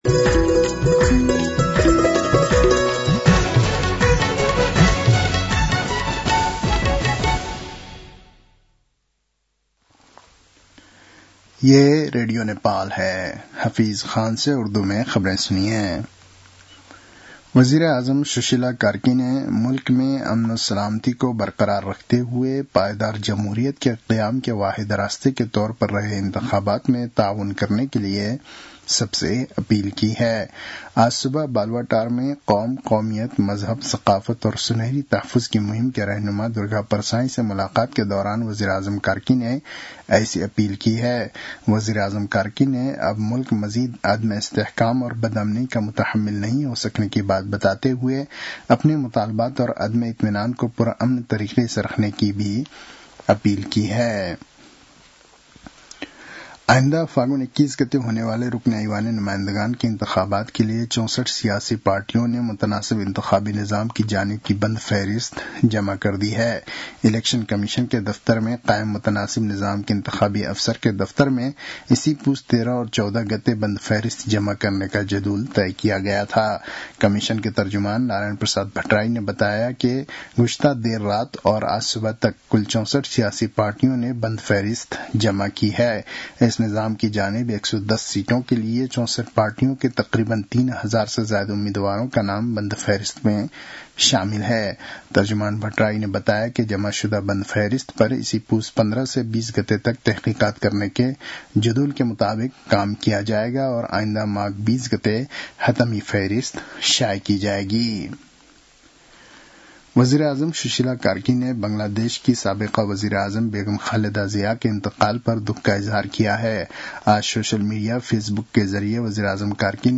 उर्दु भाषामा समाचार : १५ पुष , २०८२